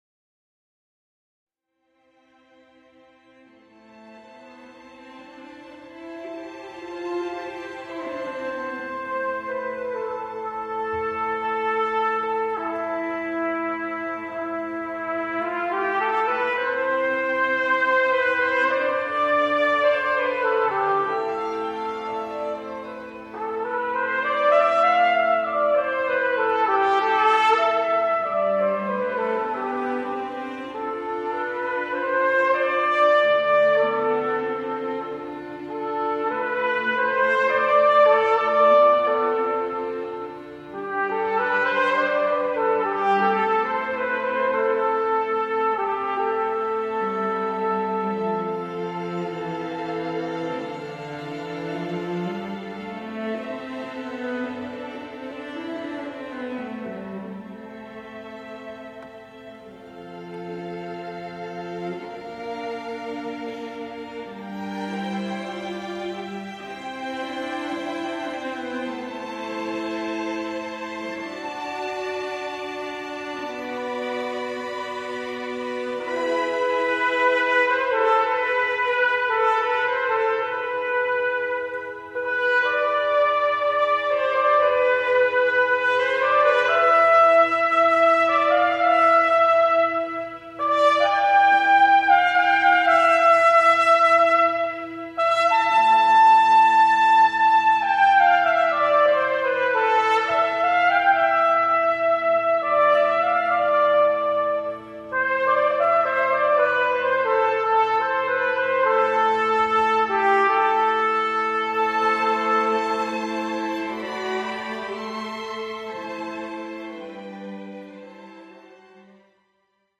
Trumpet
Prayer of St. Gregory (live
Prayer+of+St.+Gregory+(live).mp3